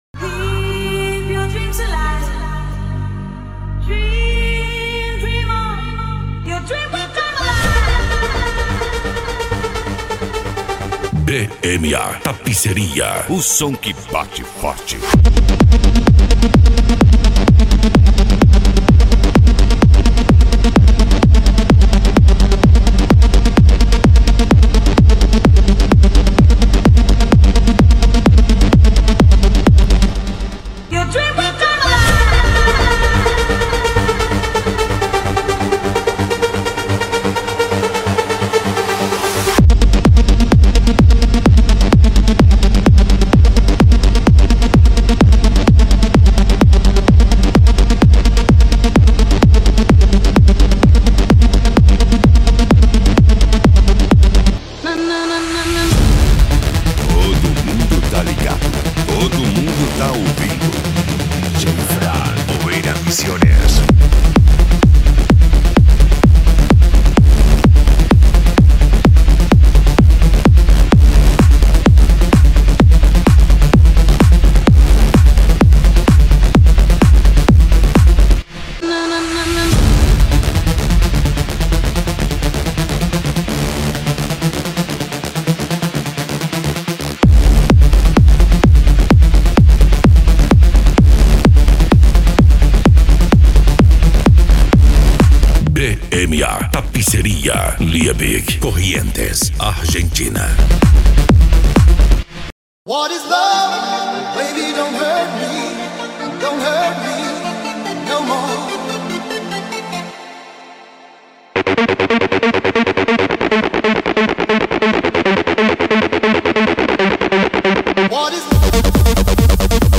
Deep House
Electro House
Eletronica